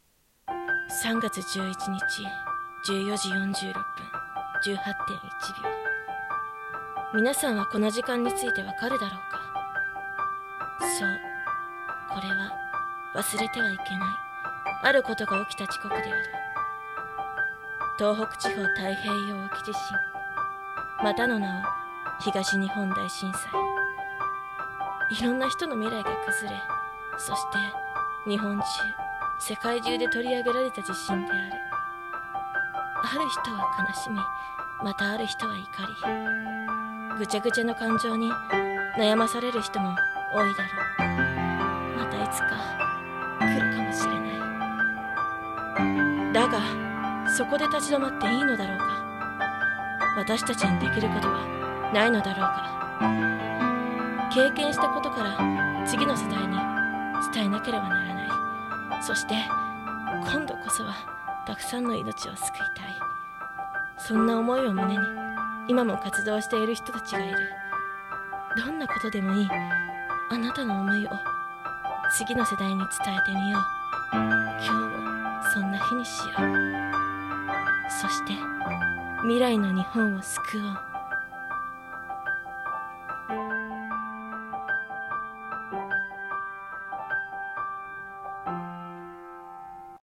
bgm使用